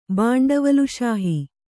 ♪ bānḍavaluśahi